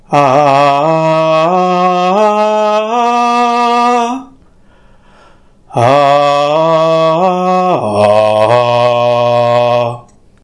Example of tones in Mêlázêla
Mêlázêla_tones.ogg